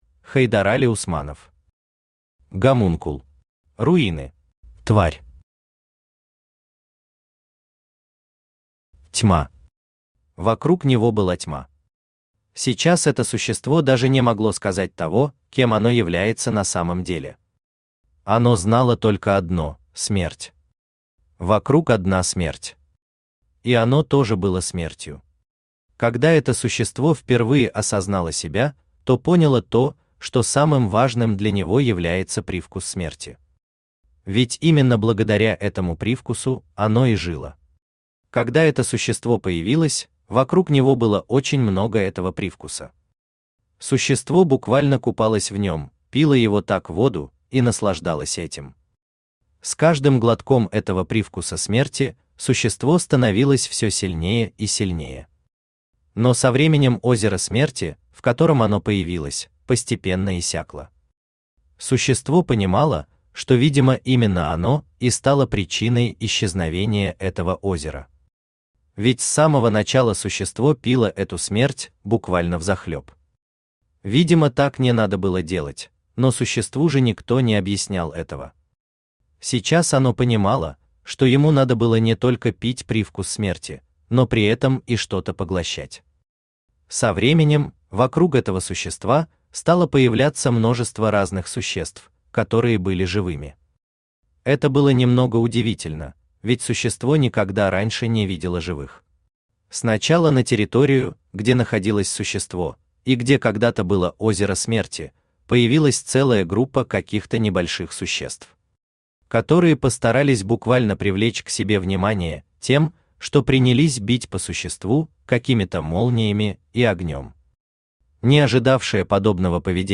Аудиокнига Гомункул. Руины | Библиотека аудиокниг
Руины Автор Хайдарали Усманов Читает аудиокнигу Авточтец ЛитРес.